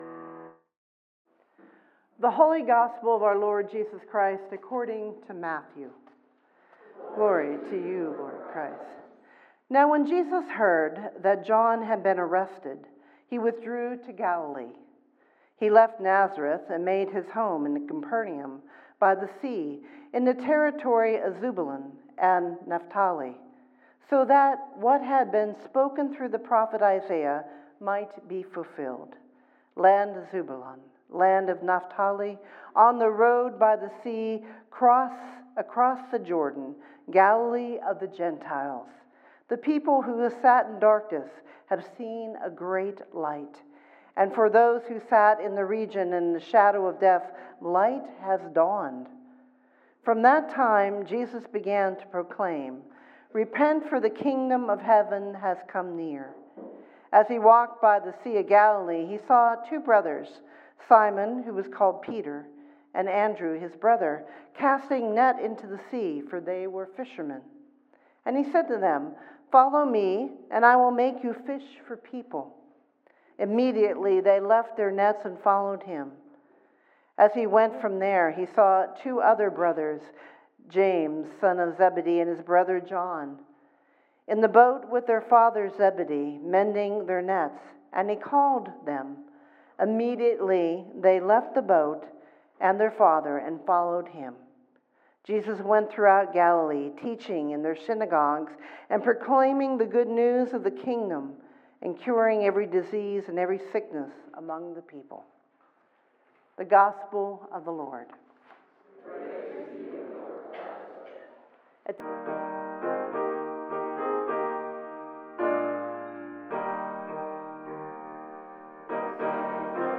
Third Sunday after Epiphany, Sermon based on 1 Cor 1:10-18